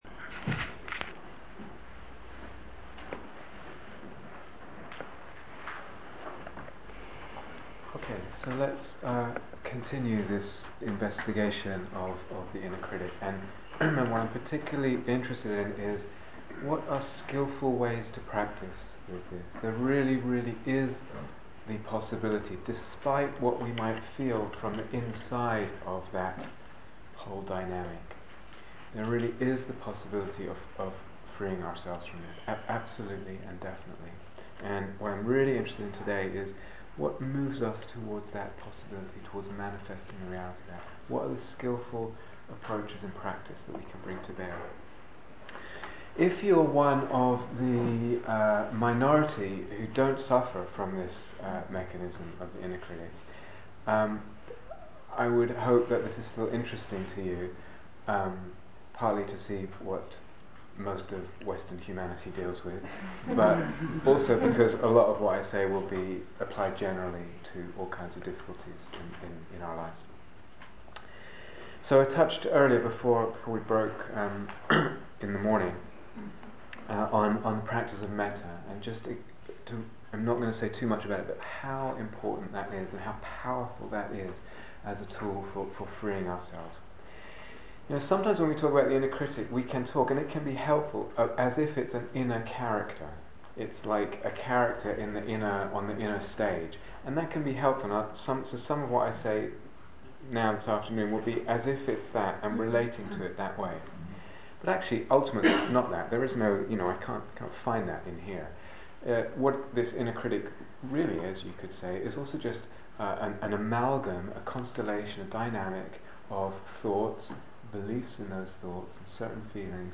Liberation from the Inner Critic - Dharma Talk